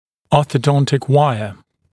[ˌɔːθə’dɔntɪk ‘waɪə][ˌо:сэ’донтик ‘уайэ]ортодонтическая проволочная дуга